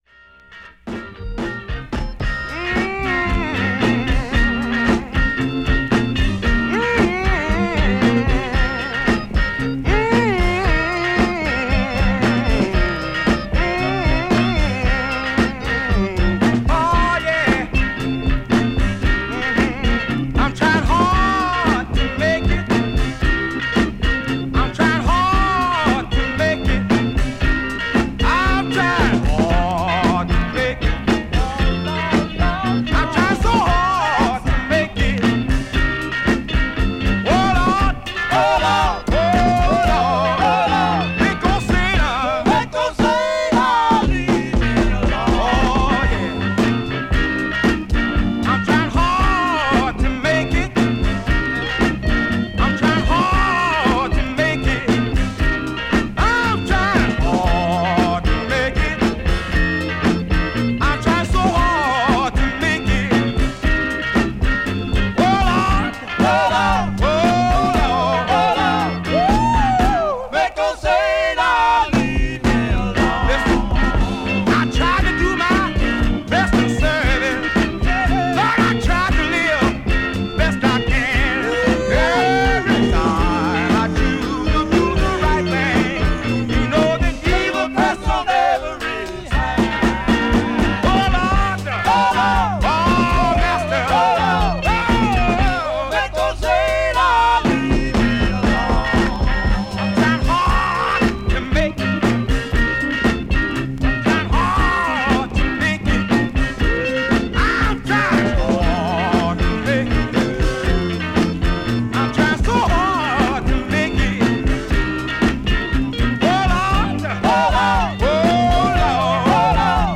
Funk/Soul